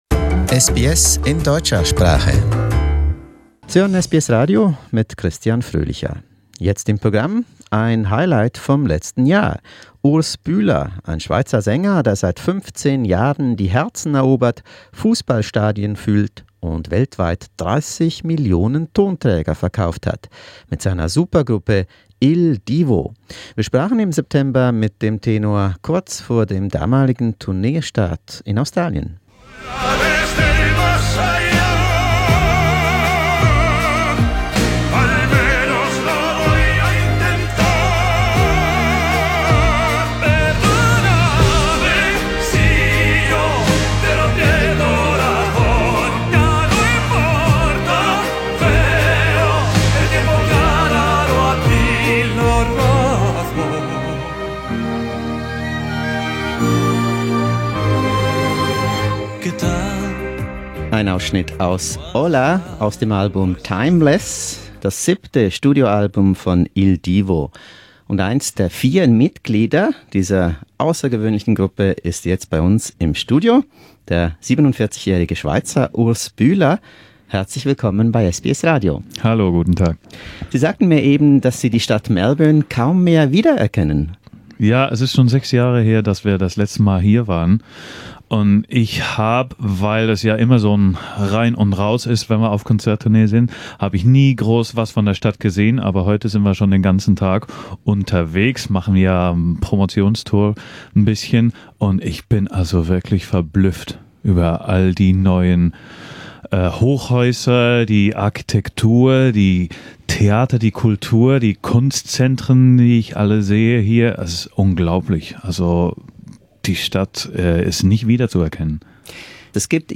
Urs Bühler im SBS Studio am Federation Square in Melbourne Source: SBS